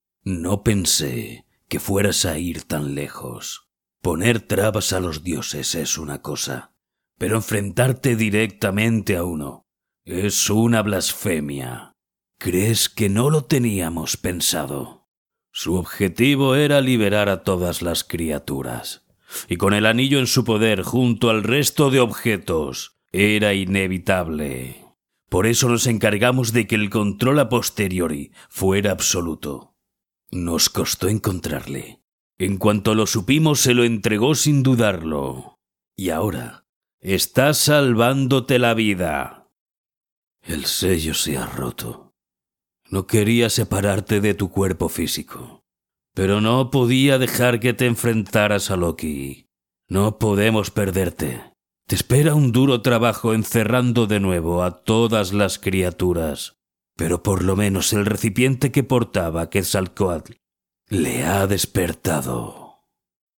Sprechprobe: eLearning (Muttersprache):
Different records, imitations, voice actor Young Voice-Adult-Senior, Soft or Hard Commercial Voice, a little bit of everything.